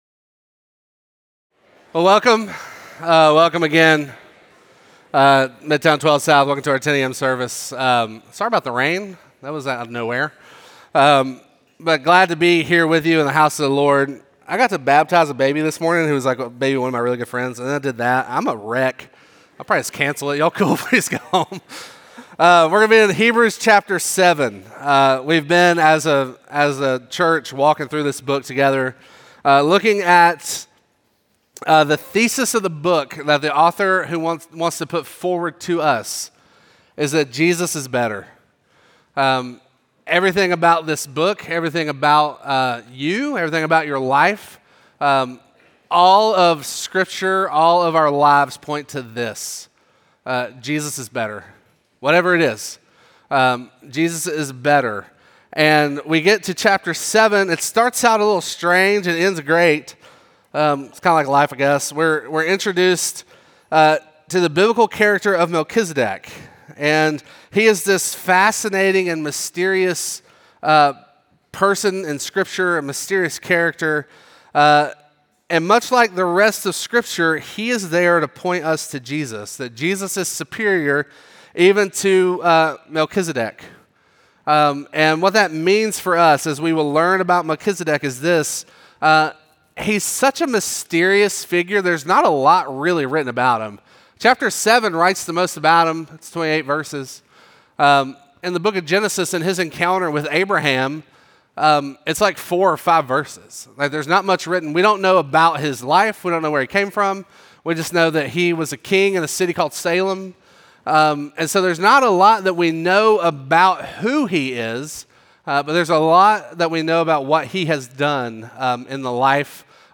Midtown Fellowship 12 South Sermons